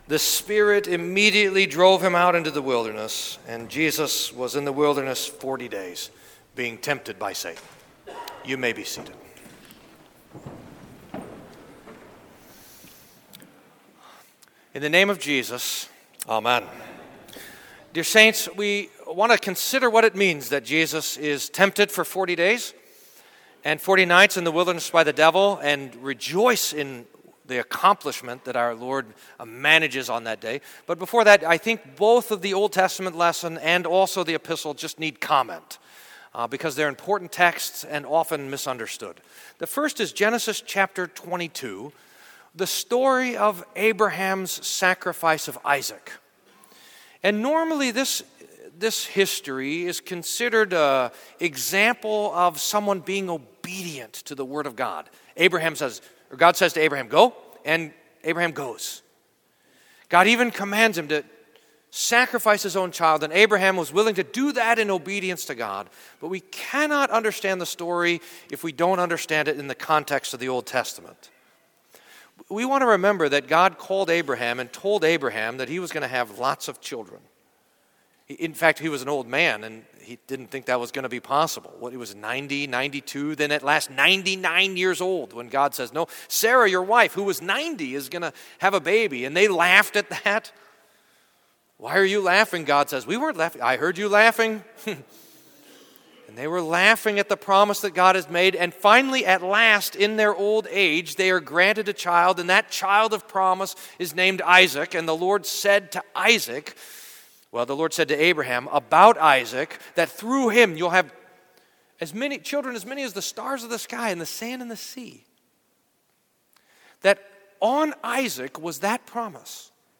Sermon for First Sunday in Lent